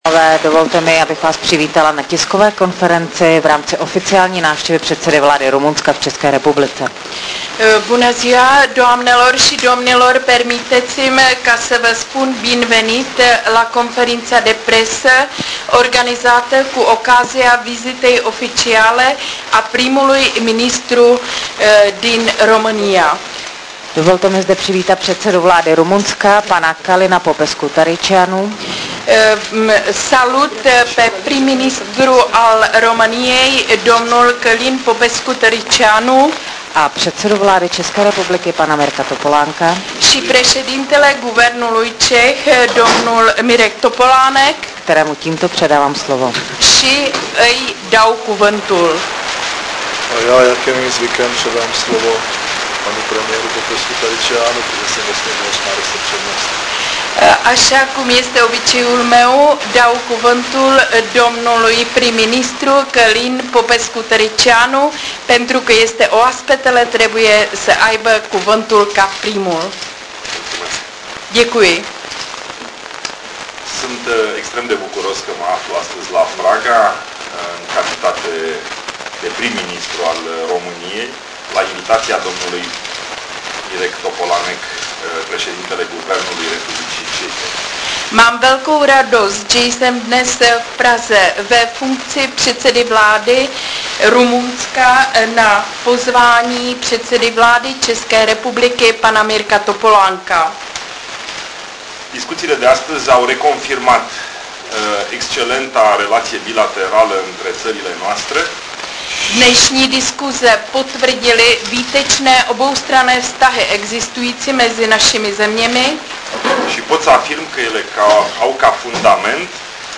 Tisková konference po setkání premiéra Mirka Topolánka a předsedy vlády Rumunska Calina Popescu-Tariceanu dne 22.1.2007 v Praze